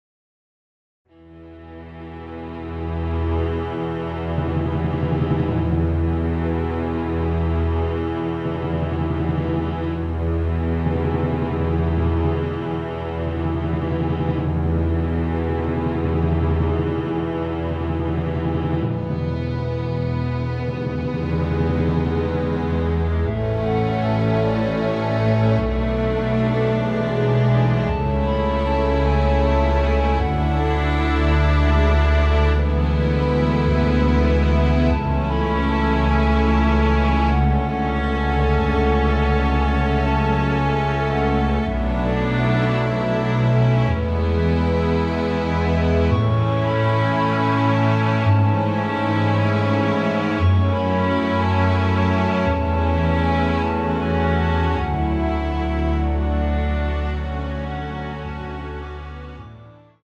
원키에서(-1)내린 멜로디 포함된 MR 입니다.(미리듣기 참조)
멜로디 MR이란
앞부분30초, 뒷부분30초씩 편집해서 올려 드리고 있습니다.
중간에 음이 끈어지고 다시 나오는 이유는